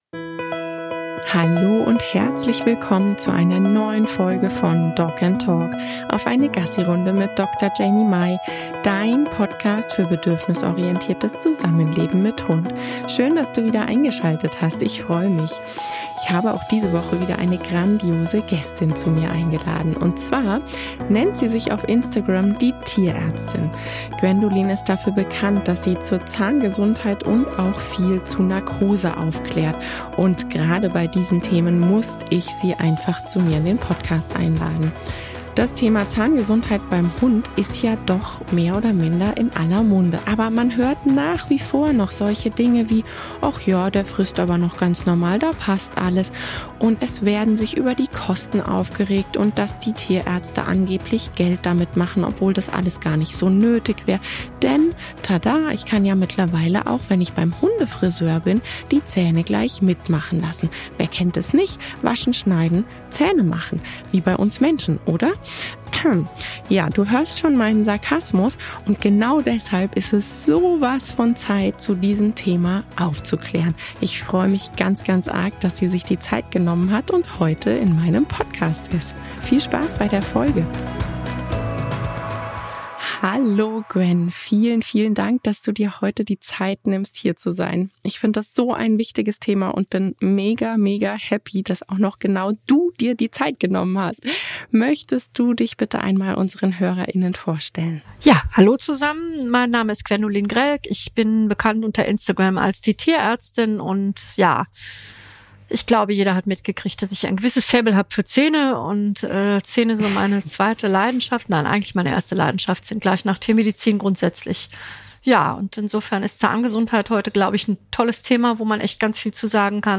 Wir haben uns in dieser Folge über gängige Mythen zur Zahngesundheit unterhalten, wie zum Beispiel die Aussage: „ Der frisst ja noch, also ist mit den Zähnen alles in Ordnung !“.